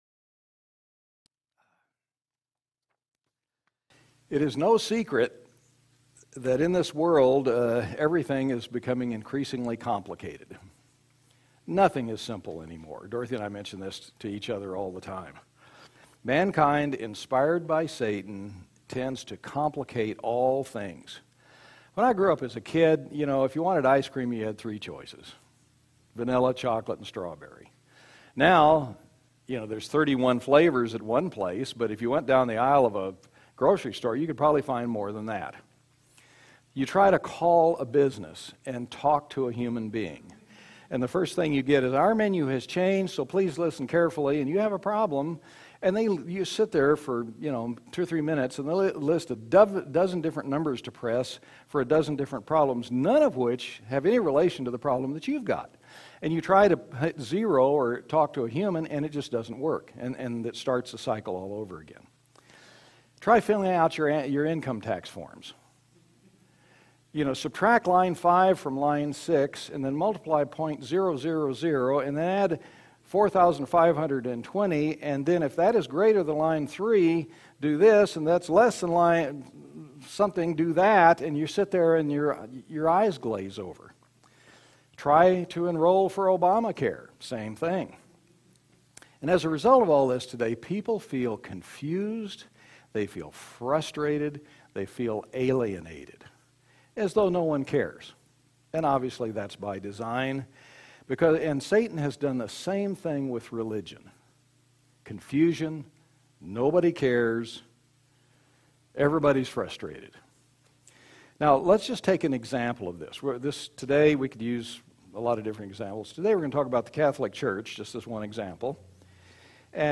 New Sermon | PacificCoG
From Location: "Moses Lake, WA"